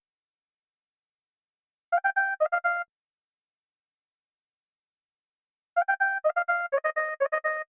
FX